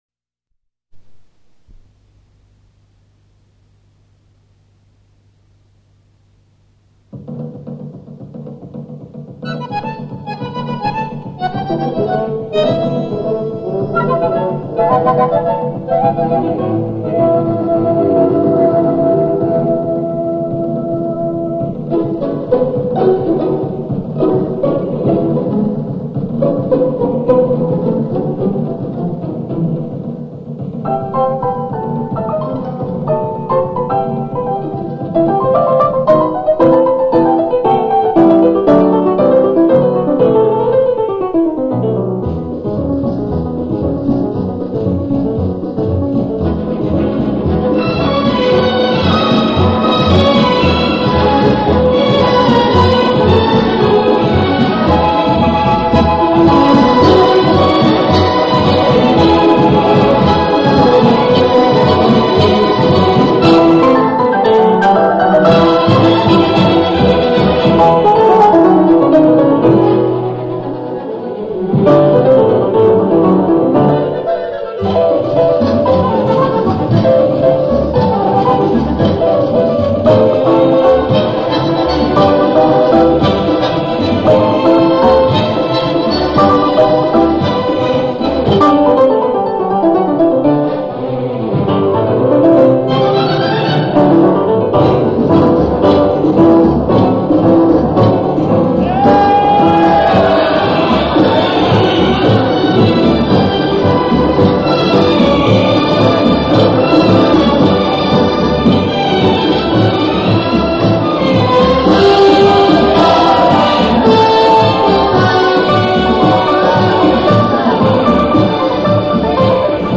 Milonga